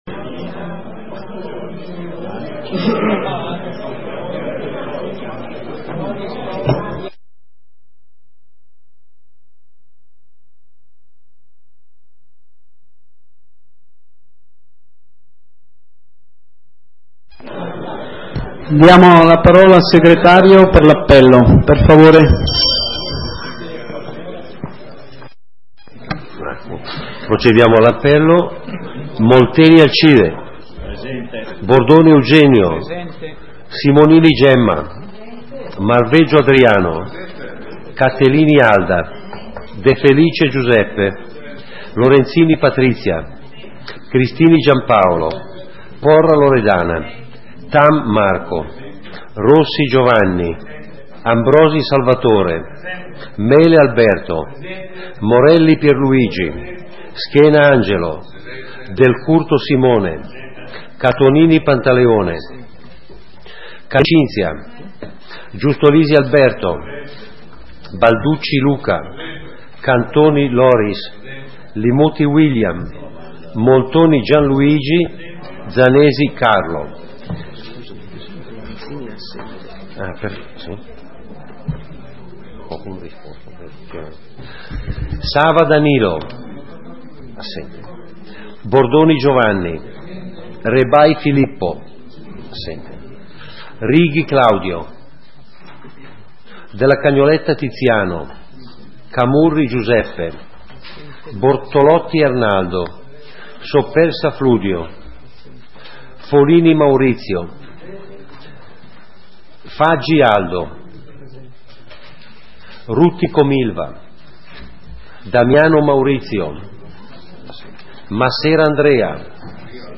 Seduta consiglio comunale del 29 aprile 2011 - Comune di Sondrio